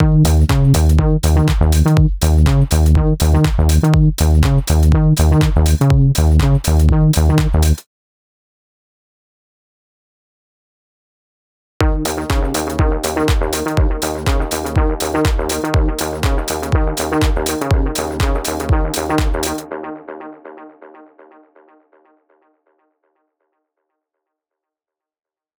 EQ, delay, reverb, and other flangers and choruses can all play a role in turning a loop from boring to exciting. EQ can be, for example, used to isolate just the high notes of a melody, while a wisely-used delay can make an otherwise slow-moving note progression more dynamic and interesting.